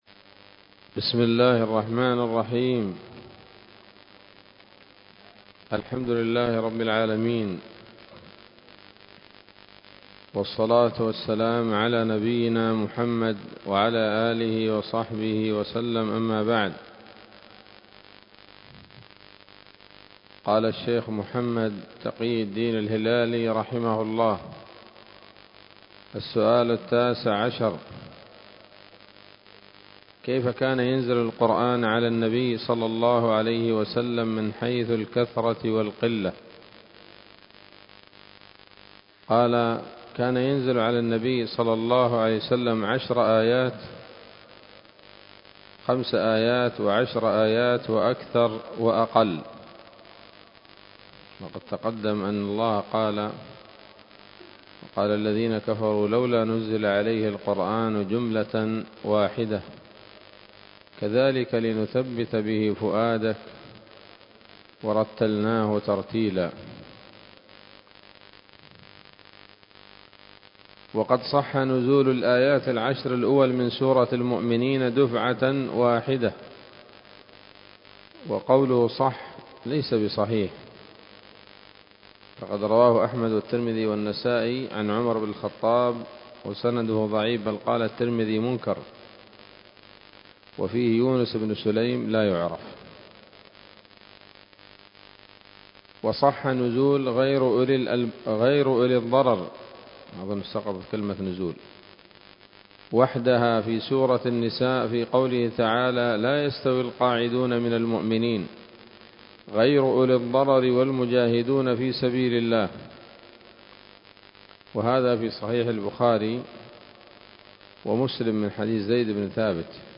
الدرس السابع من كتاب نبذة من علوم القرآن لـ محمد تقي الدين الهلالي رحمه الله